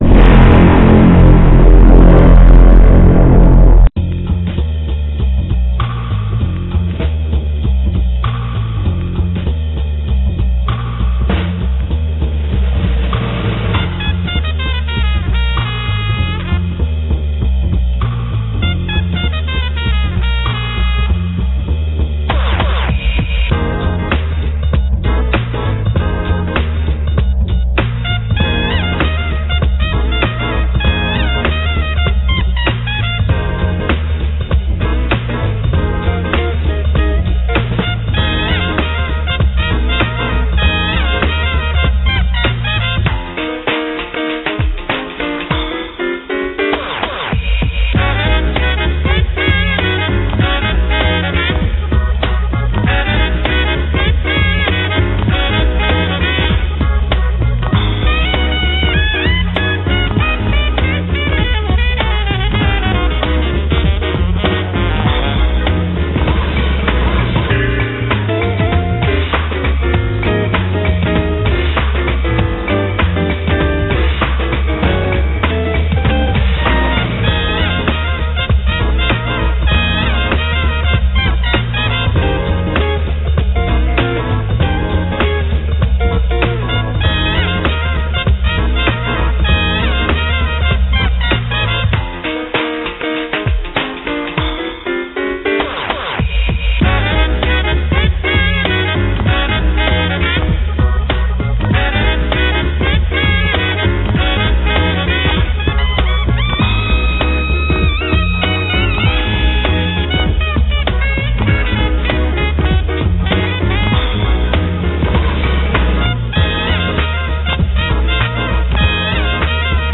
Animated Quicktime Slide Show (with music)
Photographs were taken during the celebration for participants of the Munich Science Days 2002, 15. Sept. 2002 at the Palaeontological Museum Munich.